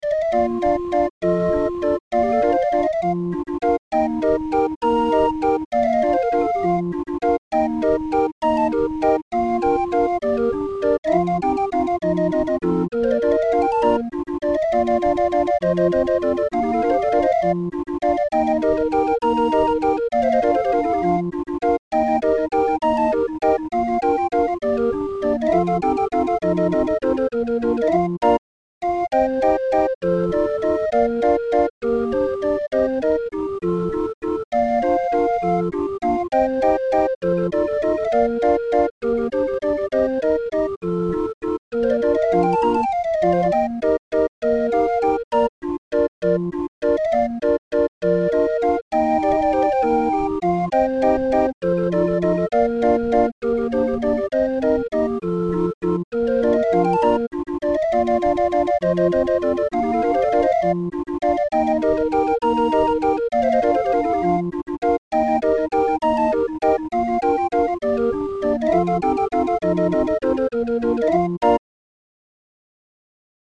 Musikrolle 20-er